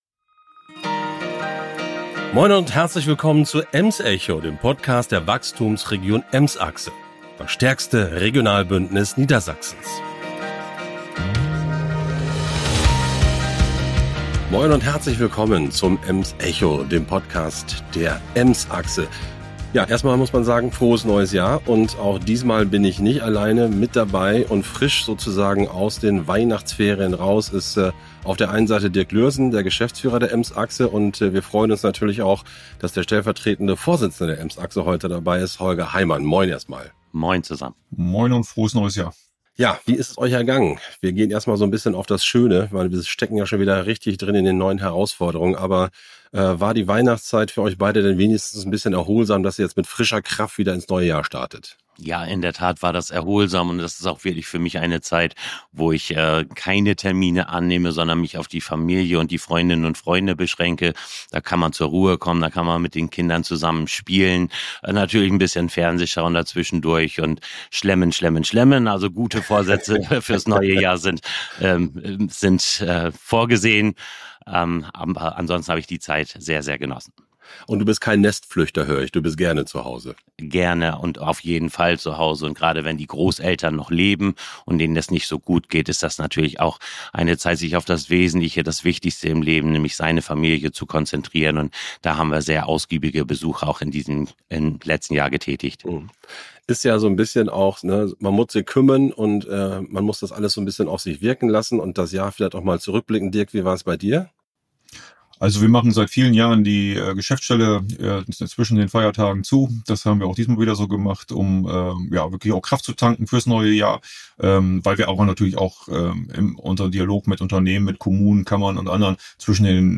Die vorgezogenen Neuwahlen zum Deutschen Bundestag nimmt das engagiert diskutierende Trio zum Anlass, die starke Region und ihre Zukunftsperspektiven näher zu betrachten.